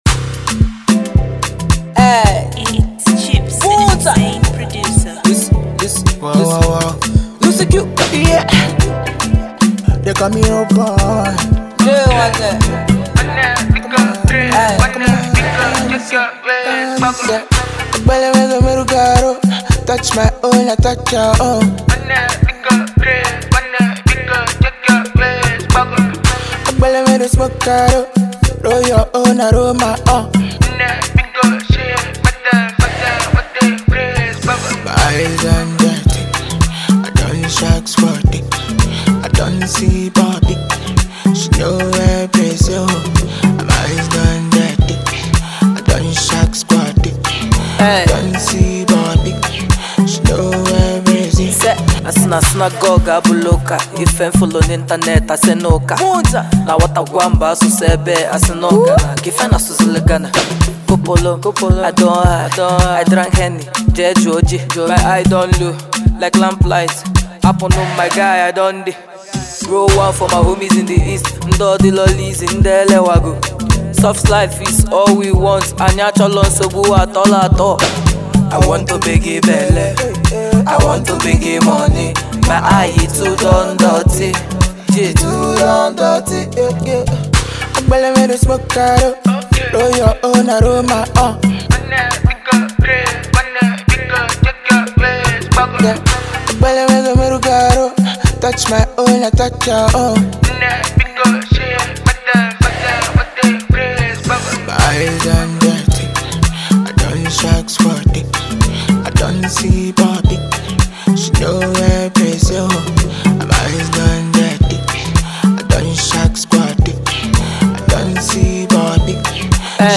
Fast rising female rapper
features Talented Singer